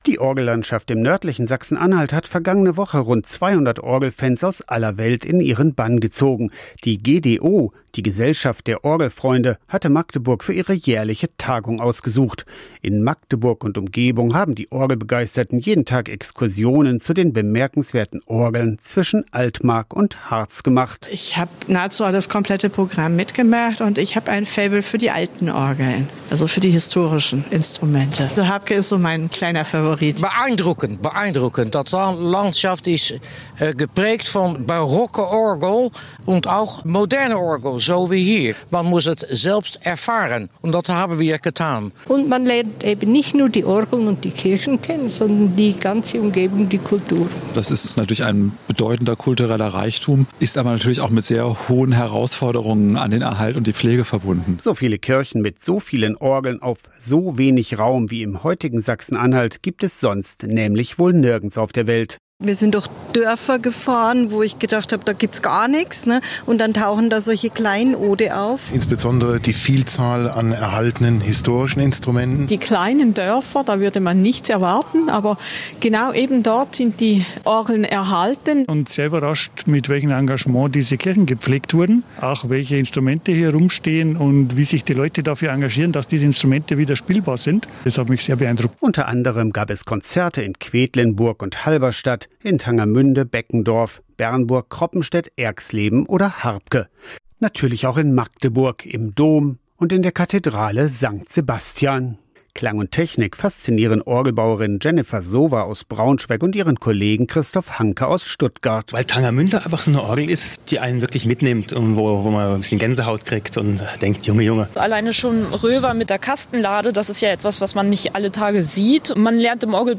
71. Internationale Orgeltagung Magdeburg